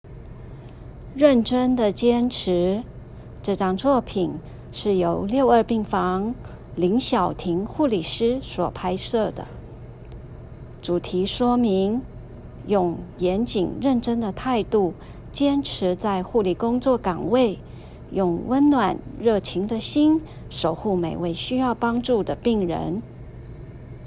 語音導覽-1.認真的堅持.m4a